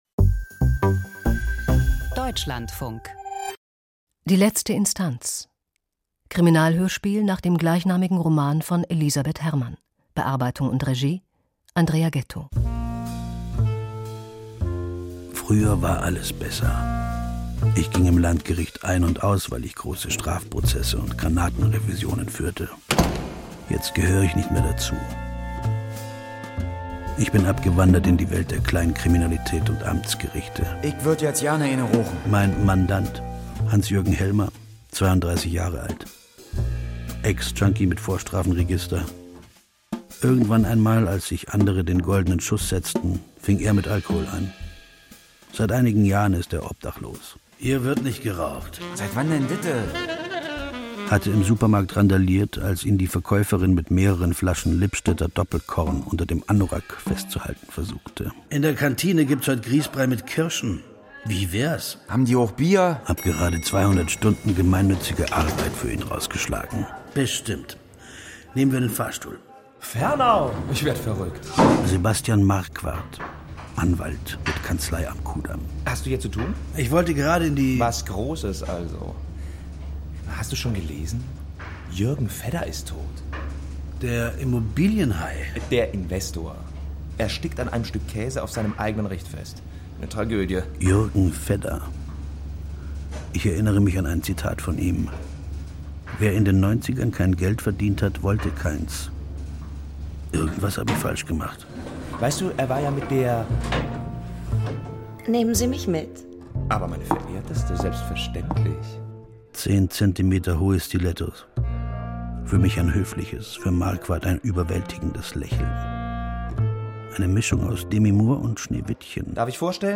Hörspiel: Von Antike bis heute, wie werden Held*innen gemacht?